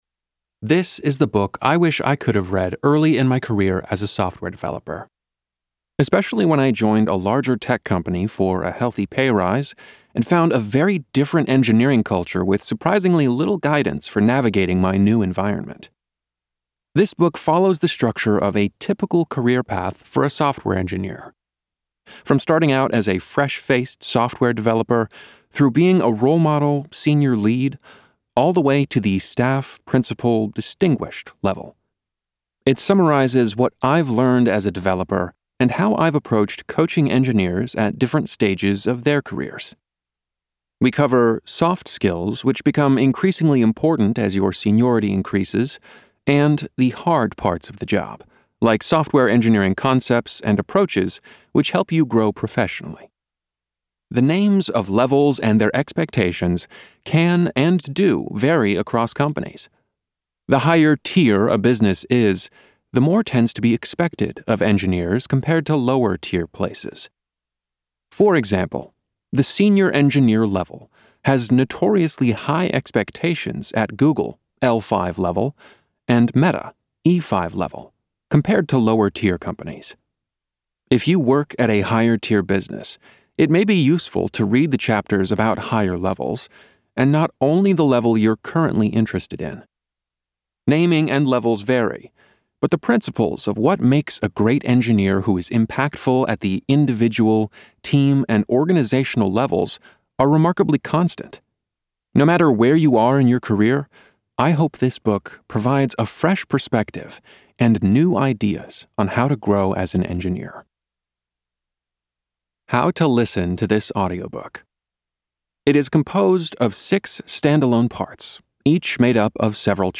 Listen to an audibook sample: Listen to a sample Available as a direct purchase , and on Audible , Spotify , Apple Books , Google Play , and other platforms.